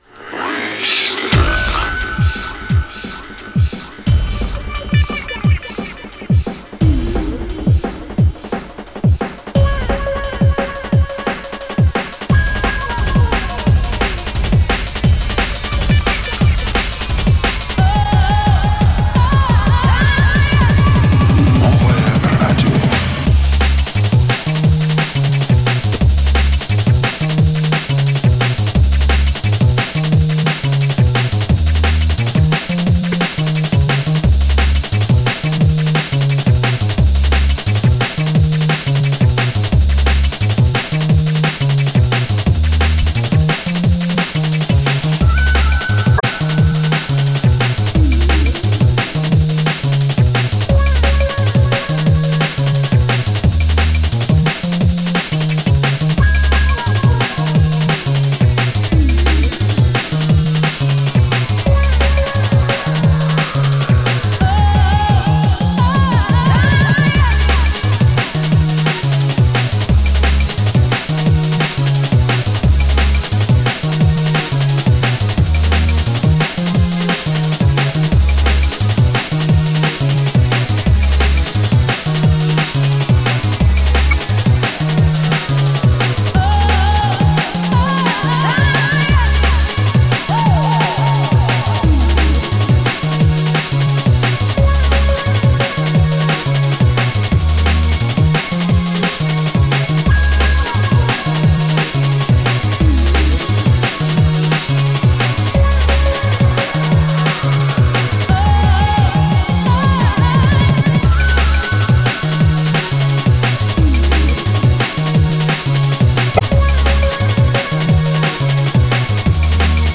darkstep, hardstep, liquid funk, neurofunk, the best